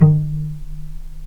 vc_pz-D#3-pp.AIF